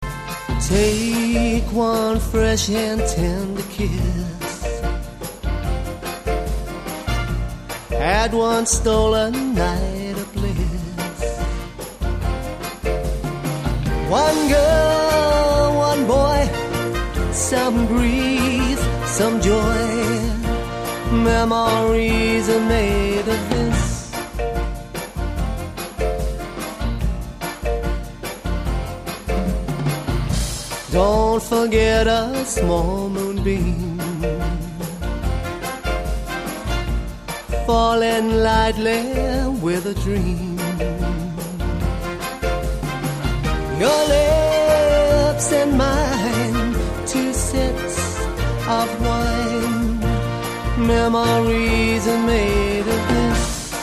Keyboard Vocal
whether he is singing a beautiful gentle ballad that can silence a room or a raunchy rocky number that can get a party started.
He uses top quality sound equipment and the best backing tracks to accompany him.